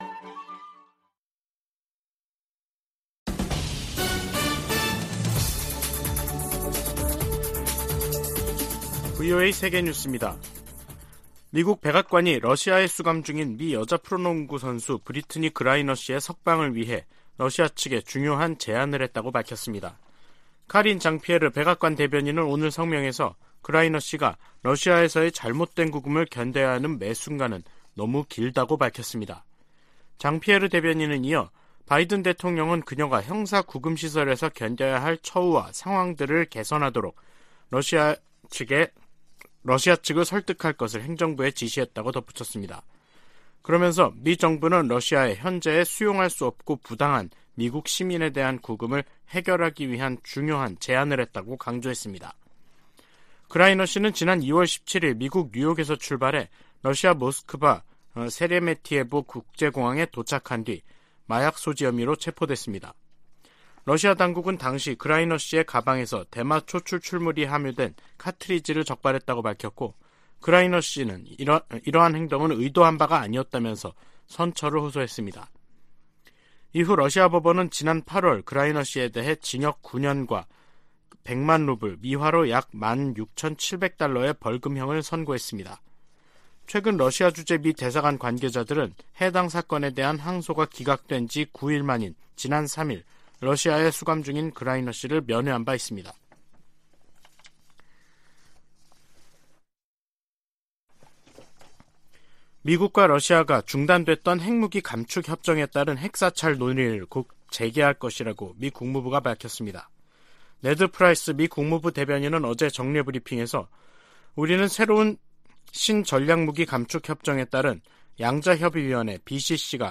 VOA 한국어 간판 뉴스 프로그램 '뉴스 투데이', 2022년 11월 9일 3부 방송입니다. 북한이 9일 오후 평안남도 숙천 일대에서 동해상으로 단거리 탄도미사일 1발을 또 발사했습니다. 8일 투표를 끝낸 미국 중간선거는 개표를 진행하고 있습니다. 공화당이 의회를 장악할 경우 미국의 한반도 정책에 어떤 변화가 생길지 주목되고 있습니다. 미 국방부는 북한이 러시아에 포탄을 제공하고 있다는 기존 입장을 재확인하며 상황을 계속 주시할 것이라고 밝혔습니다.